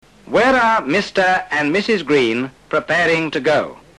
Recuerda que a partir de esta lección los audios son más veloces.